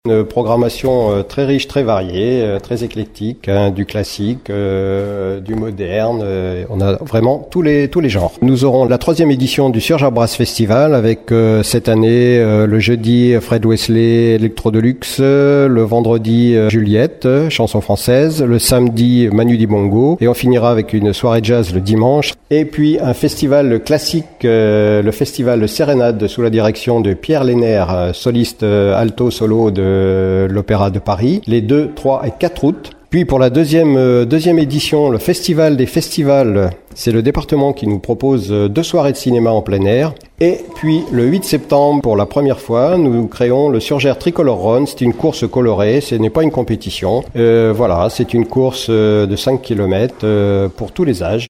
Pierre Vivier, adjoint à la culture de la mairie de Surgères, nous détaille le programme de l’été :